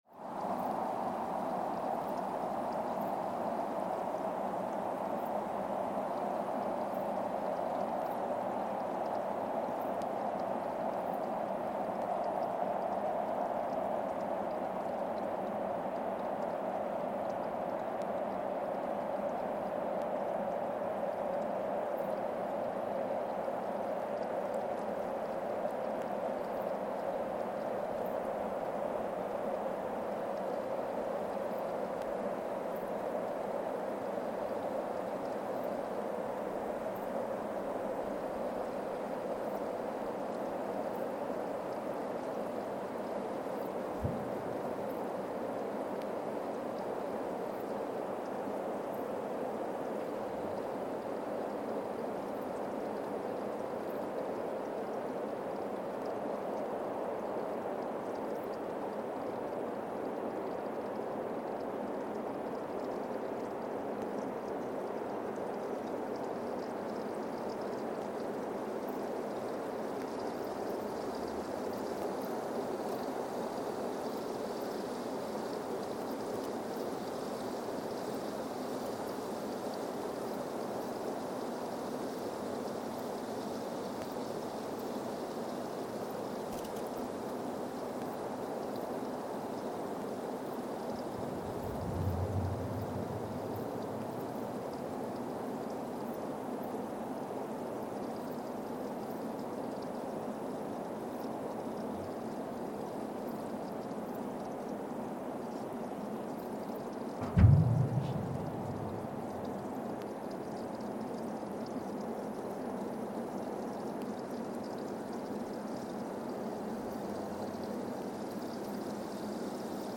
Weston, MA, USA (seismic) archived on December 30, 2024
Station : WES (network: NESN) at Weston, MA, USA
Sensor : CMG-40T broadband seismometer
Speedup : ×1,800 (transposed up about 11 octaves)
Loop duration (audio) : 05:36 (stereo)